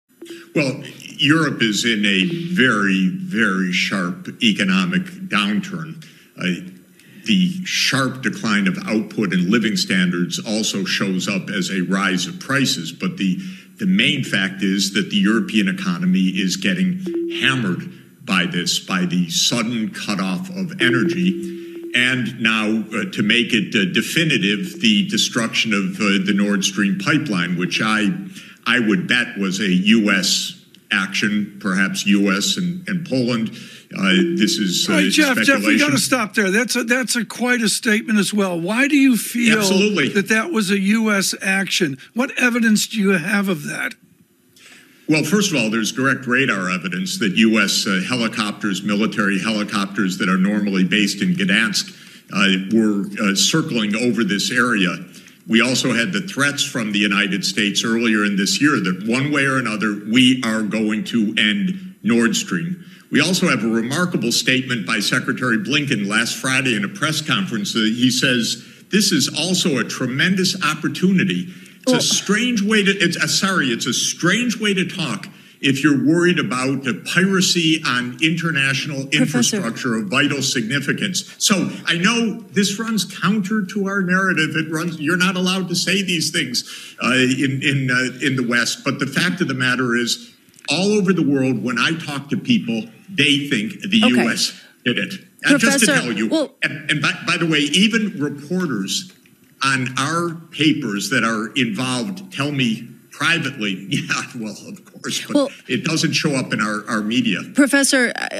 Der Wirtschaftswissenschaftler und Analyst für öffentliche Politik Jeffrey Sachs lässt im Live-Fernsehen von Bloomberg eine wahre Bombe platzen: "Ich würde wetten, dass es (die Zerstörung der Nord Stream-Pipeline) eine US-Aktion war".